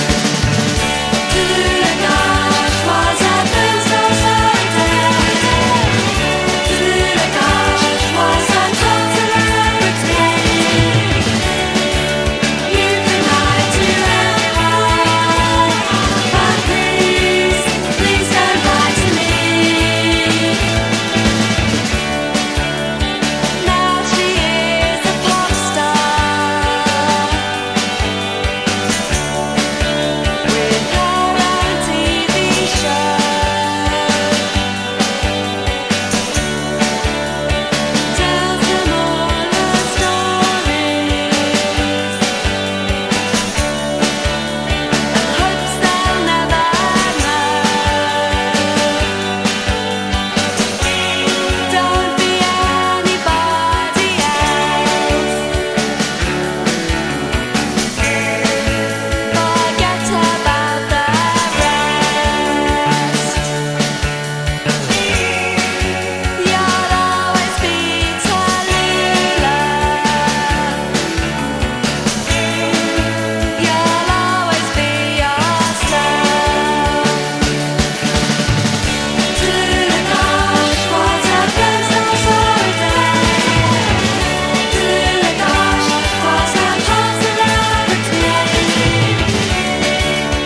レジェンド・オブ・アノラック/TWEEポップ！
緩急展開するアレンジも最高な